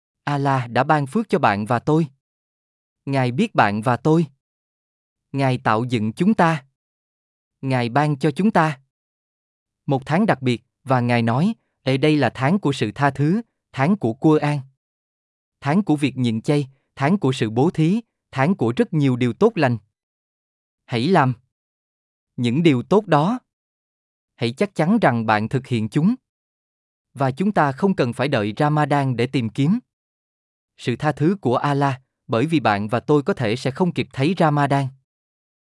صوتية مدبلجة باللغة الفيتنامية عن أهمية شهر رمضان ومكانته في الإسلام. تتناول الأبعاد الروحية والاجتماعية لهذا الشهر المبارك وكيف يُعد فرصة فريدة للتقرب من الله...